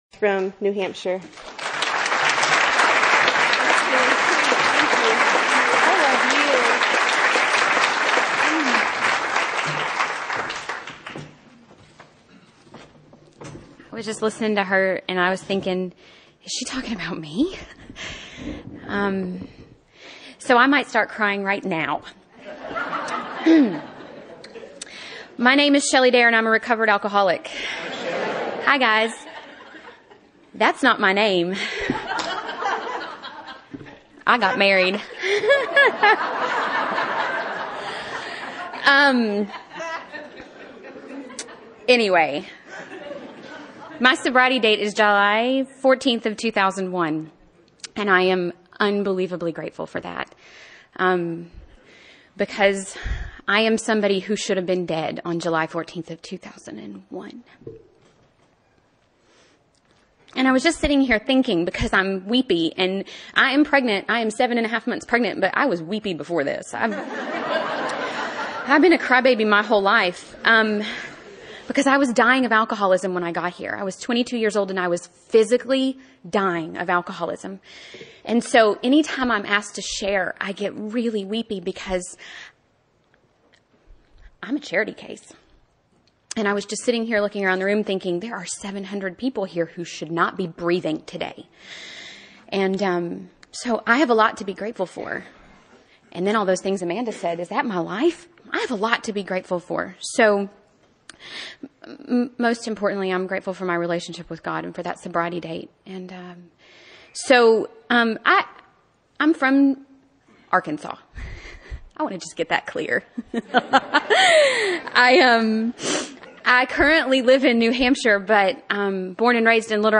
Redemption through 12-Step Alcoholism Recovery – Women AA Speakers